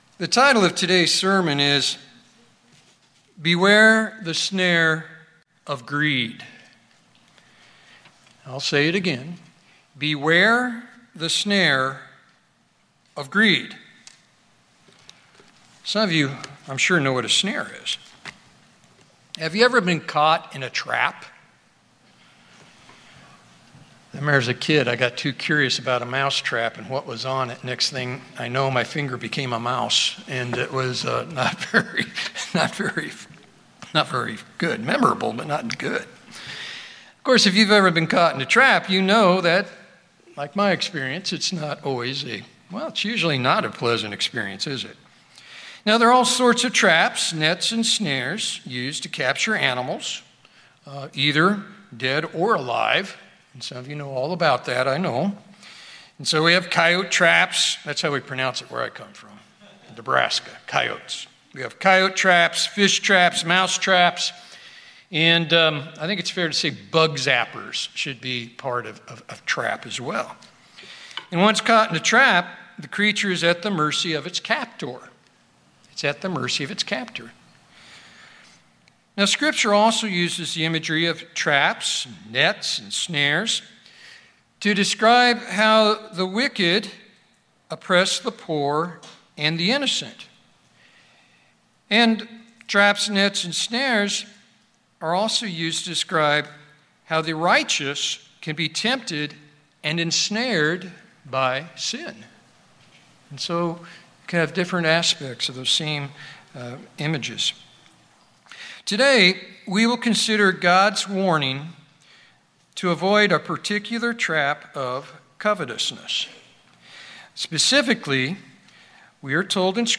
In this sermon, we will consider God’s warning to avoid a particular trap of covetousness. Specifically, we must guard ourselves from the snare of greed.